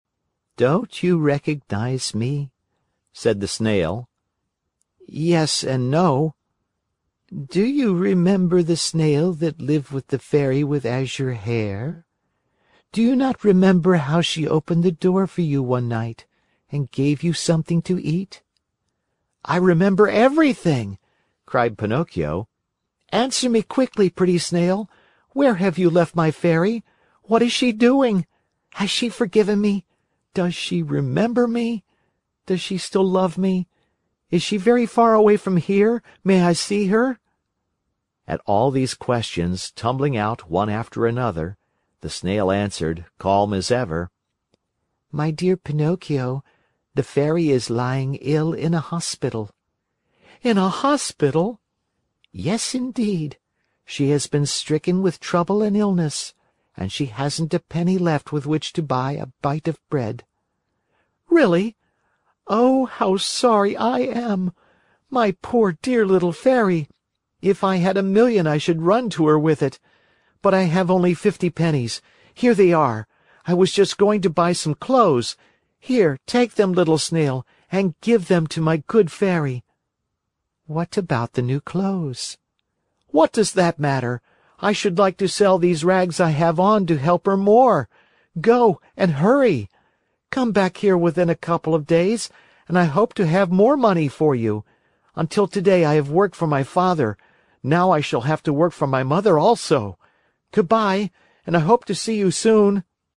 在线英语听力室木偶奇遇记 第164期:匹诺曹梦想成真(10)的听力文件下载,《木偶奇遇记》是双语童话故事的有声读物，包含中英字幕以及英语听力MP3,是听故事学英语的极好素材。